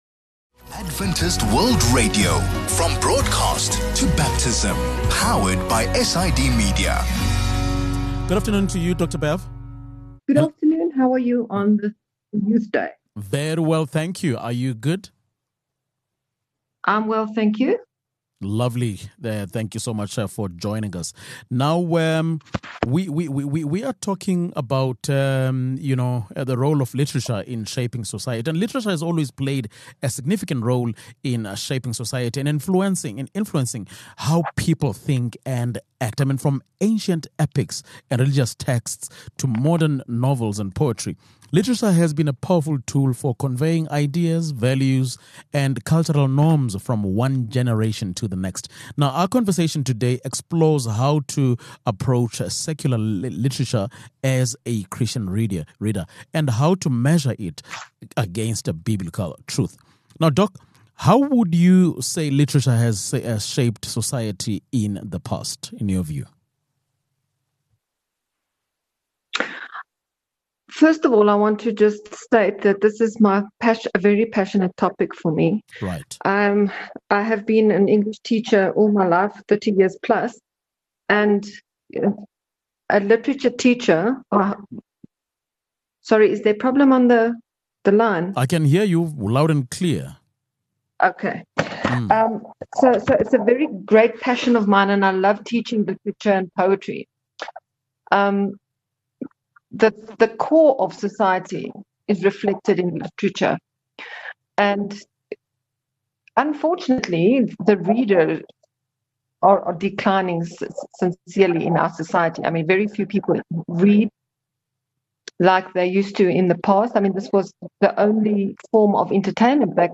Today’s conversation explores how to approach secular literature as a Christian reader, and how to measure it against Biblical truth.